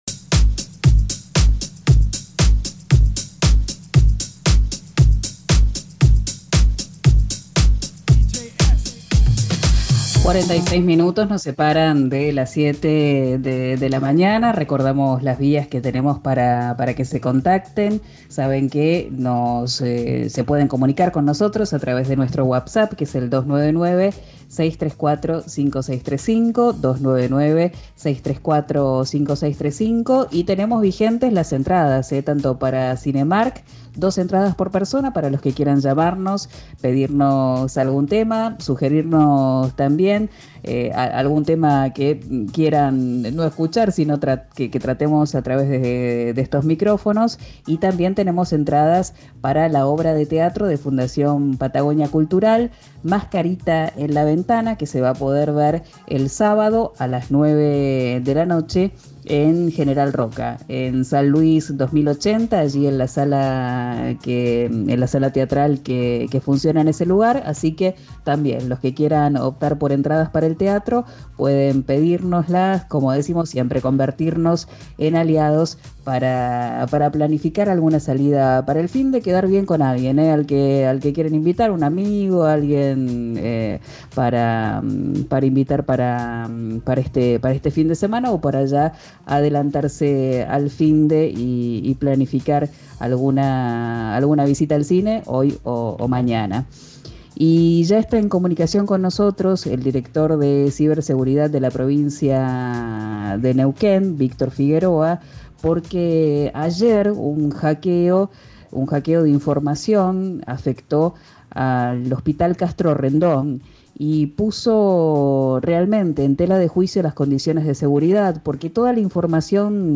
El director de Ciberseguridad de la Provincia de Neuquén, Víctor Figueroa, dialogó con RÍO NEGRO RADIO y contó acerca de los pasos a seguir y las últimas novedades.